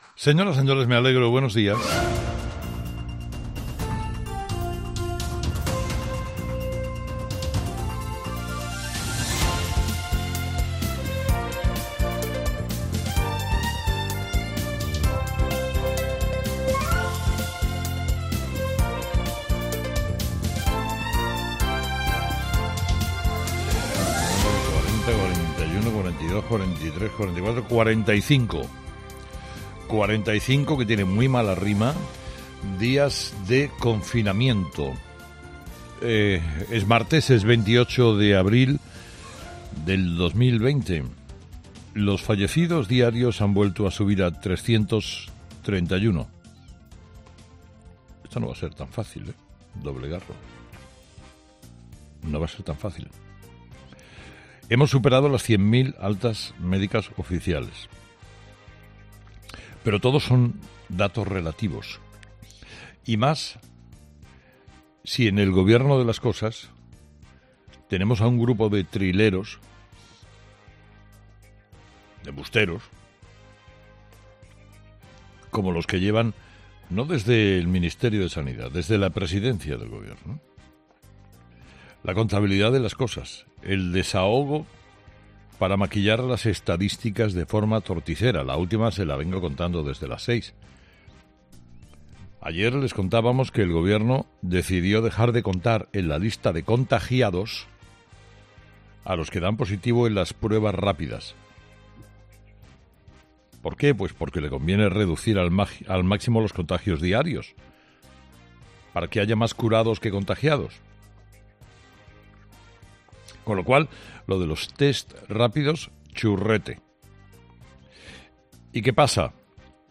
ESCUCHA EL EDITORIAL DE CARLOS HERRERA